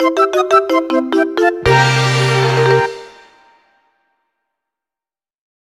theme